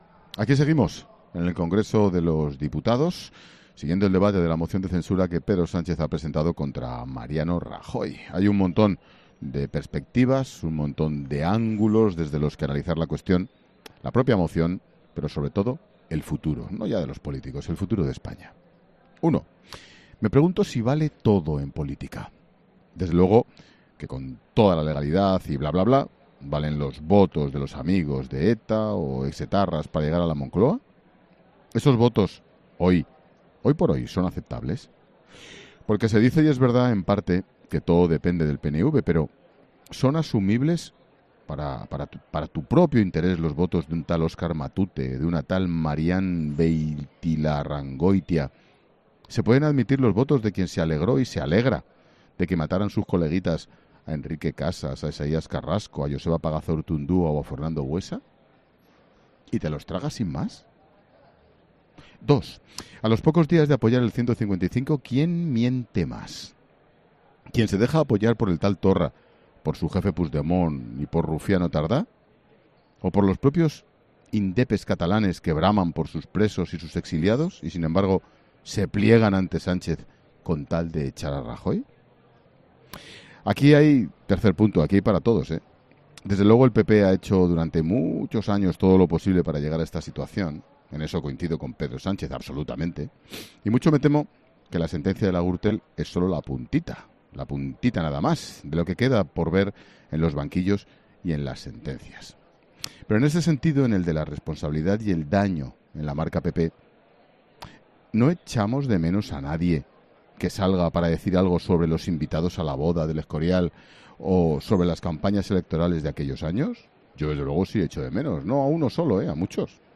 Ángel Expósito se pregunta en su monólogo de este jueves si se puede llegar a la presidencia del Gobierno a cualquier precio: "¿Vale todo en política? Desde luego que con toda la legalidad y bla, bla, bla, pero...¿Valen los votos de los amigos de ETA o exetarras para llegar a La Moncloa?", ha dicho en clara alusión a la moción de censura de Sánchez, que saldría a favor con los votos de Podemos, ERC, Bildu, PNV y PdeCAT.